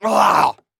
Plants vs. Zombies sounds (звуки из игры) Часть 3